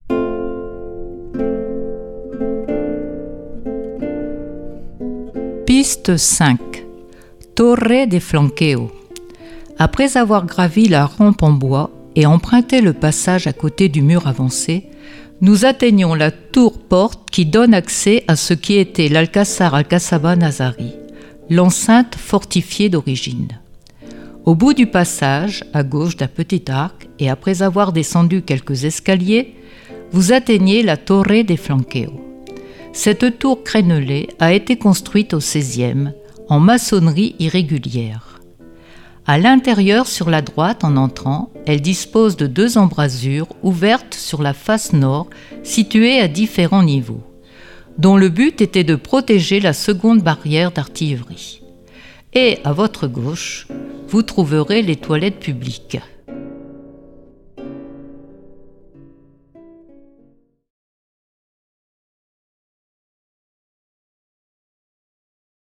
Audioguide Castillo de Salobreña